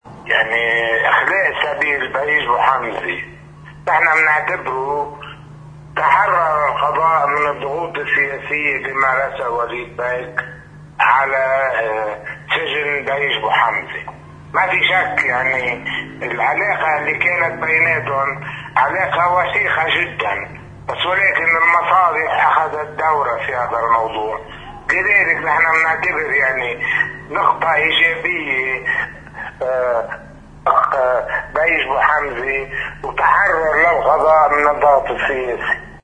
مقتطف من حديث الأمين العام لحركة النضال اللبناني العربي النائب السابق فيصل الداوود لموقعنا